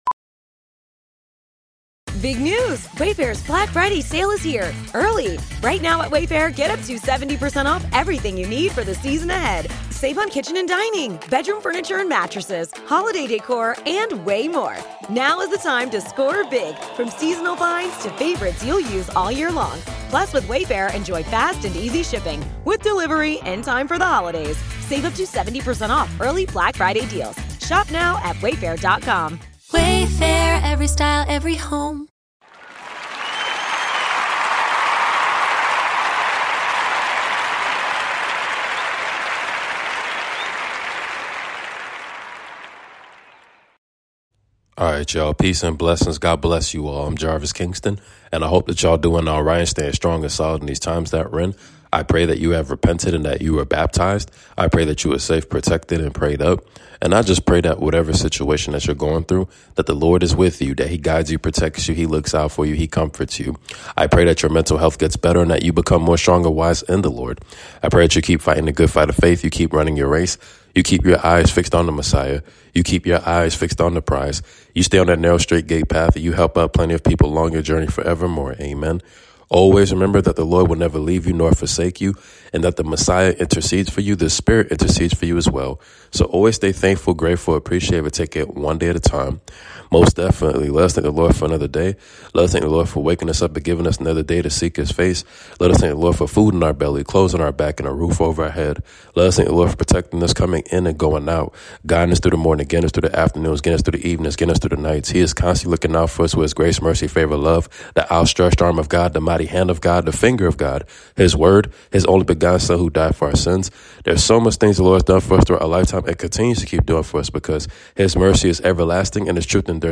A daily devotion based on healing and transformation. 1 John 5:5 ; John 5:6; Romans 8:26-28.